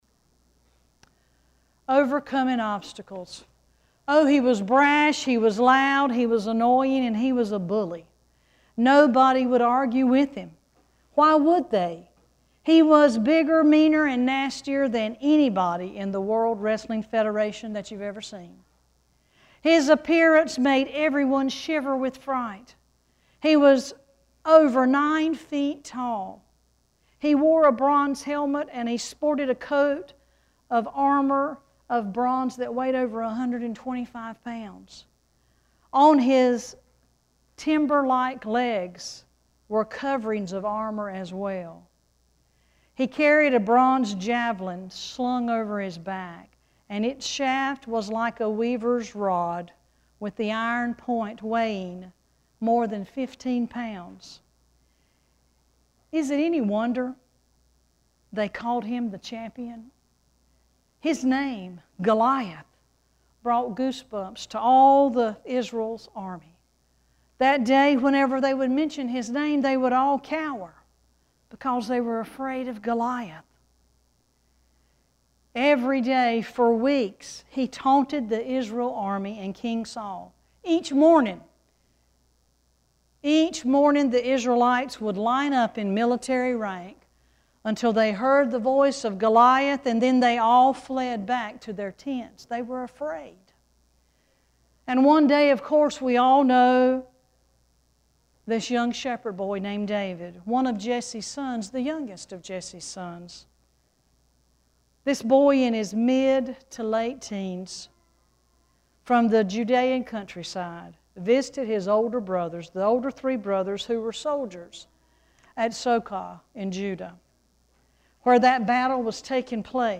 6-24-sermon.mp3